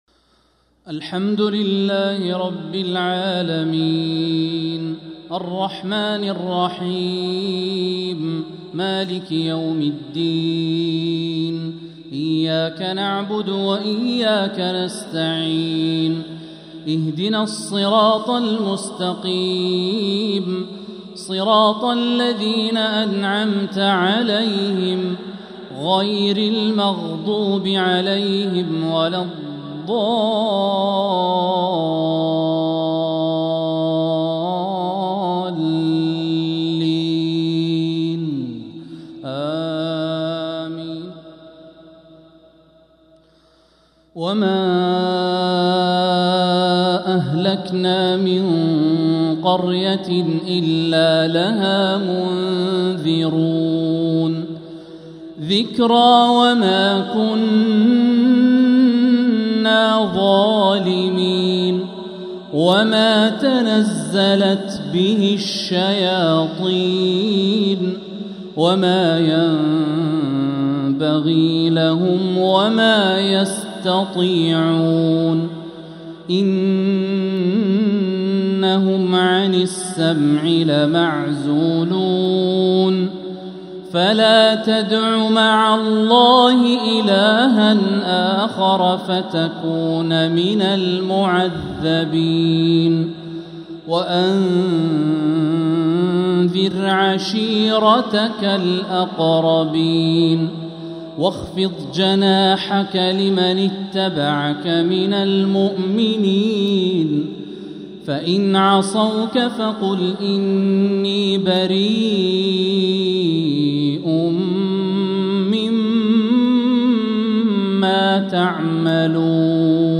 عشاء الثلاثاء 7-7-1446هـ خواتيم سورة الشعراء 208-227 | Isha prayer from surah Ash-Shu'araa 7-1-2025 🎙 > 1446 🕋 > الفروض - تلاوات الحرمين